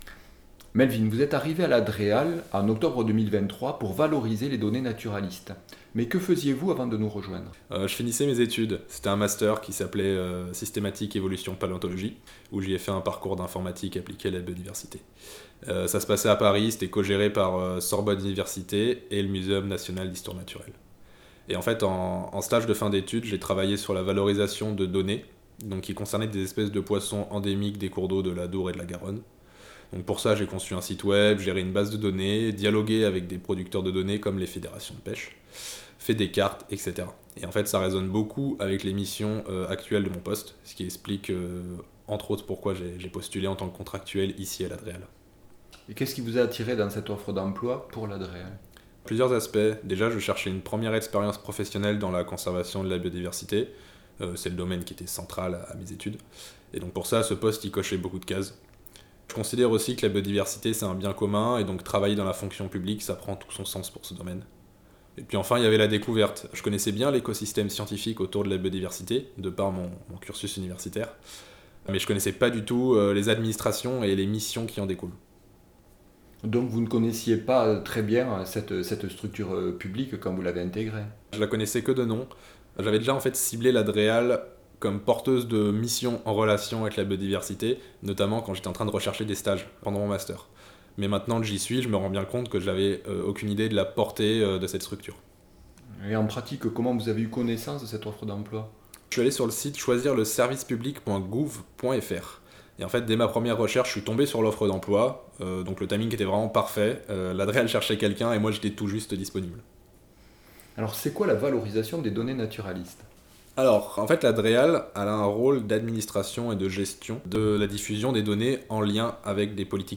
Interview d'un contractuel biodiversité